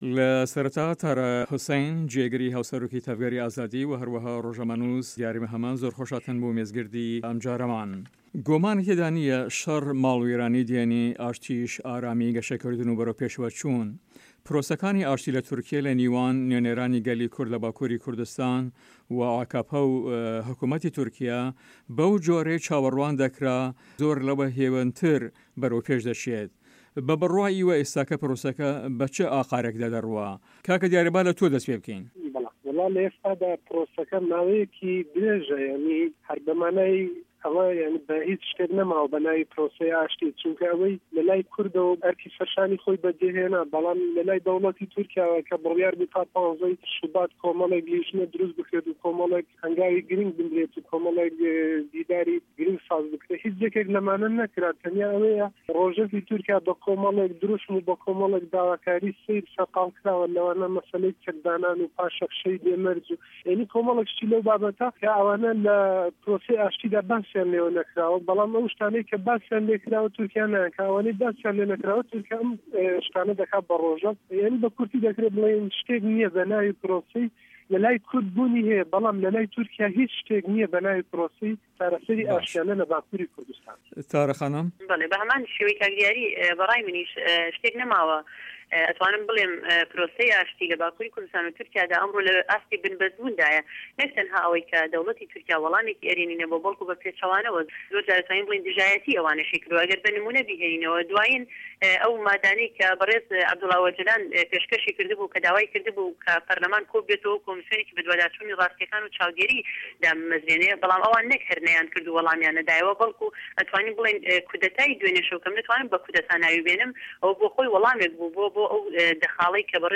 مێزگرد: تورکیا له‌ دوو ریانی شه‌ر و ئاشتی دا